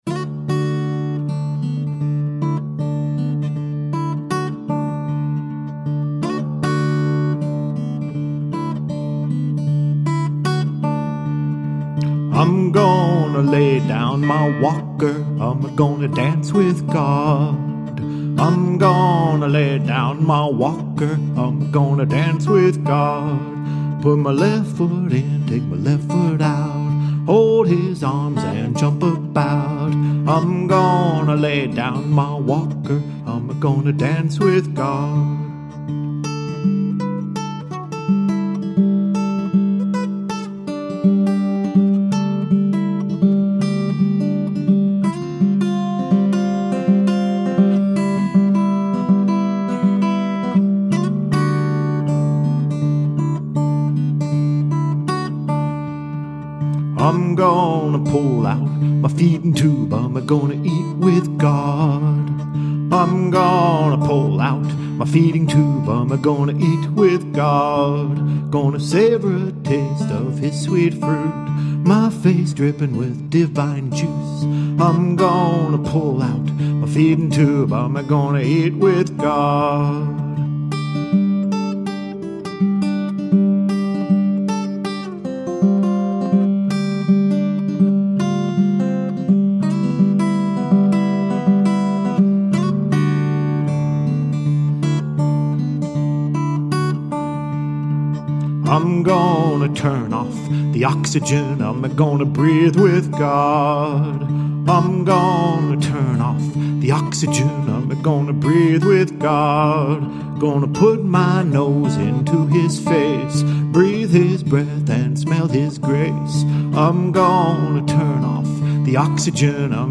Gospel take on facing death with joy in DADGAD.